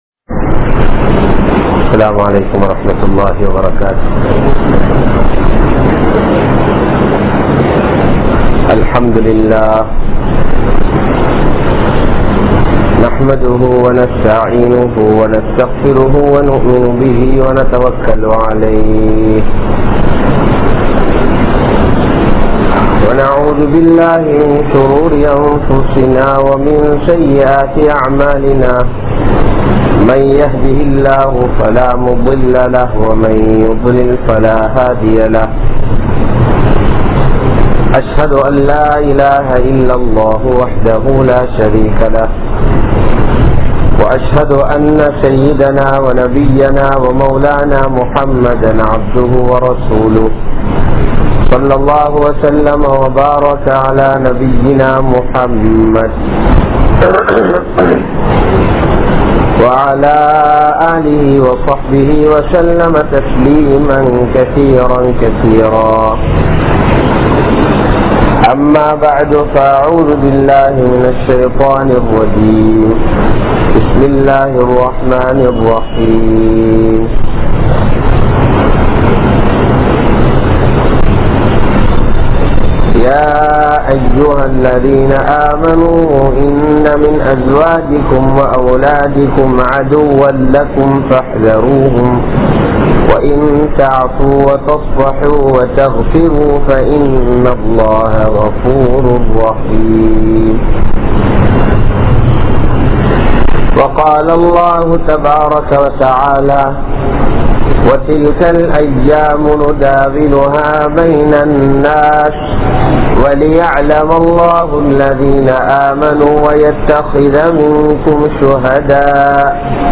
Pillaihaludan Peasa Theriyaatha Petroarhal | Audio Bayans | All Ceylon Muslim Youth Community | Addalaichenai
Muhiyaddeen Grand Jumua Masjith